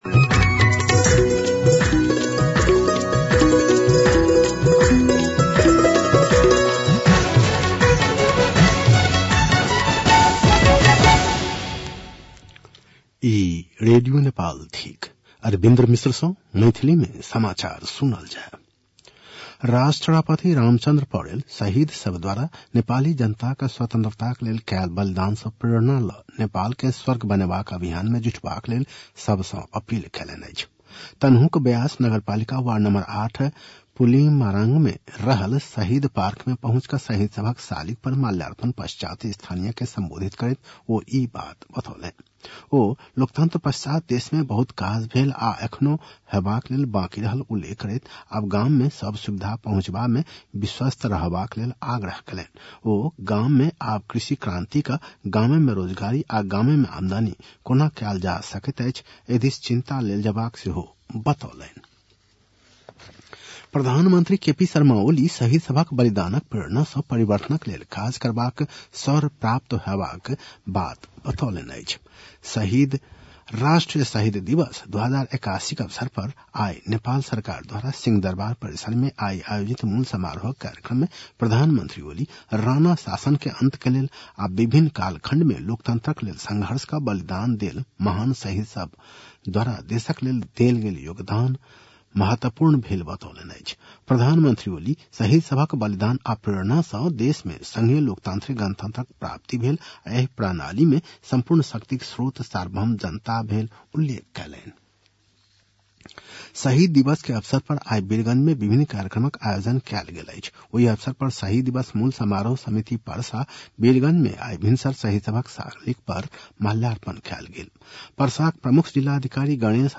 मैथिली भाषामा समाचार : १७ माघ , २०८१
Maithali-News-10-16.mp3